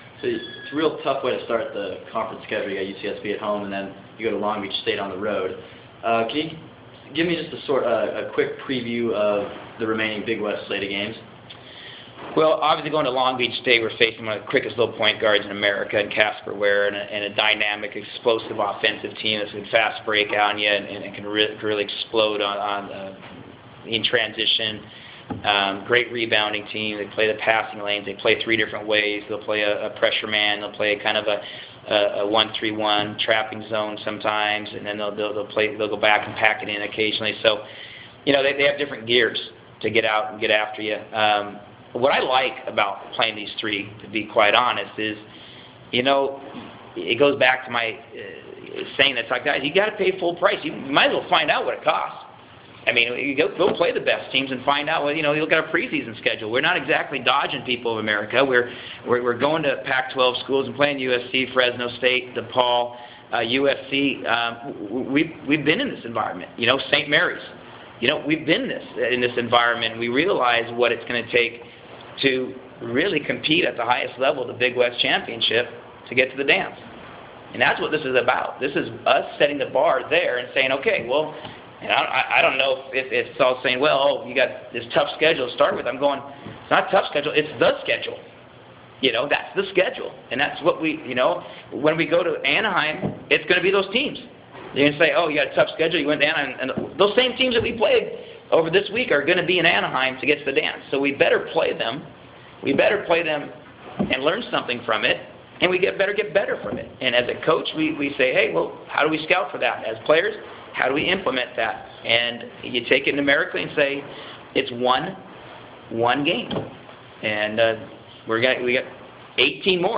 You can listen to the passion in his voice in the press conference clip below.